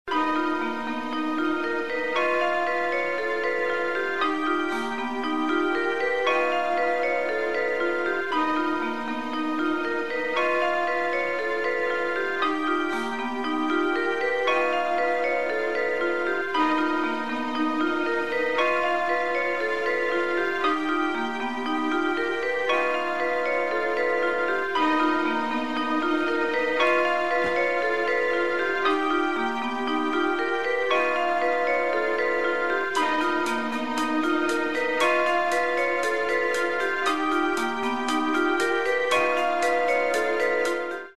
• Качество: 320, Stereo
мелодичные
спокойные
инструментальные
тревога